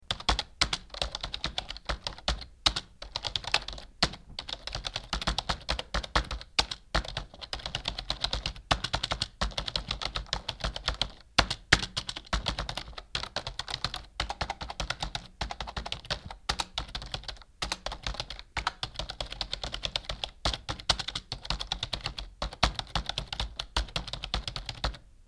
دانلود صدای تایپ 1 از ساعد نیوز با لینک مستقیم و کیفیت بالا
جلوه های صوتی
برچسب: دانلود آهنگ های افکت صوتی اشیاء دانلود آلبوم صدای کیبورد از افکت صوتی اشیاء